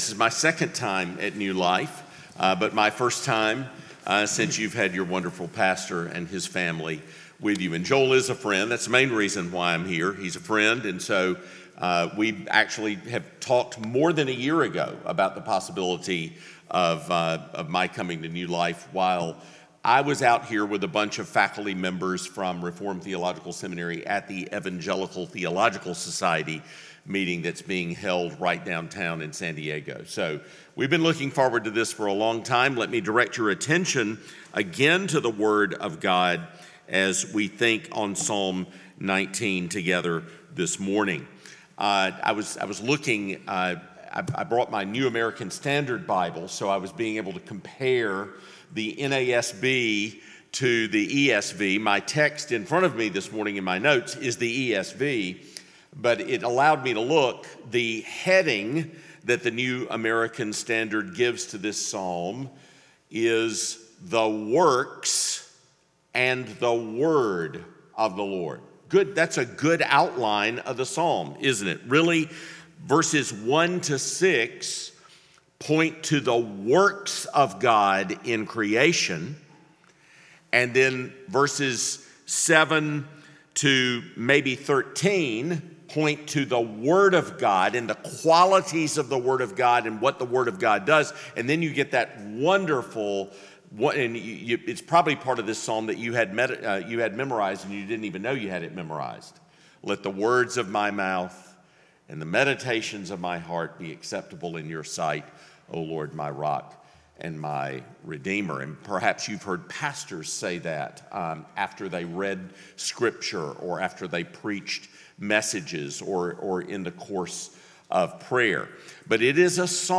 Sermons | New Life Presbyterian Church of La Mesa
Guest Speaker